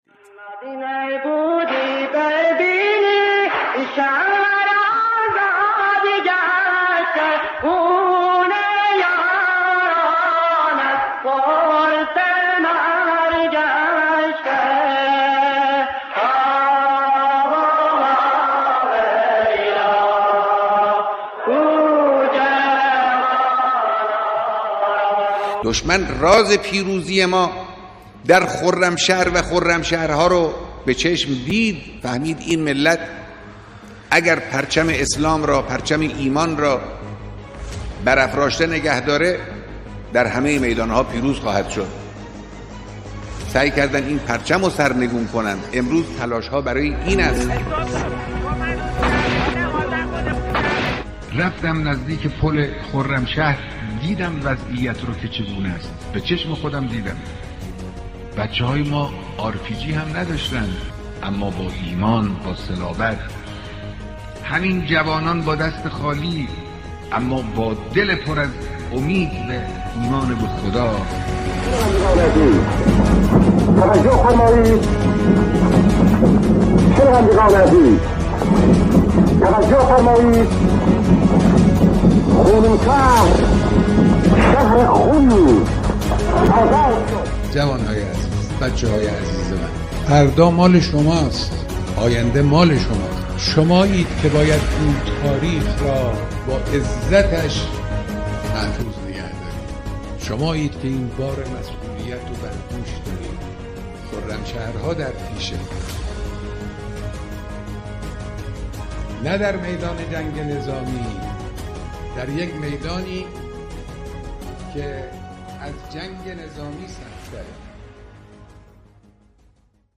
راز پیروزی در خرمشهر در بیانات مقام معظم رهبری